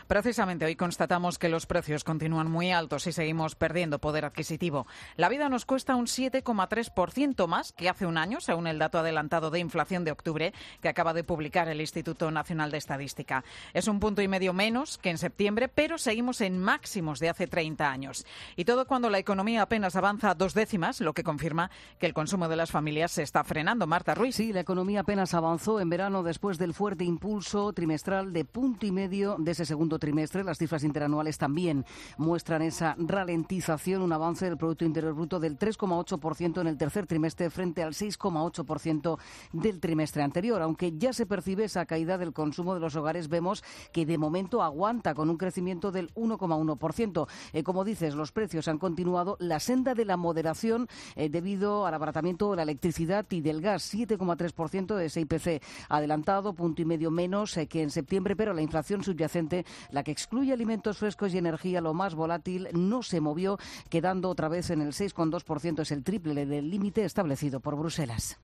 La economía apenas avanzó en verano, aunque los precios siguen moderando su subida. Crónica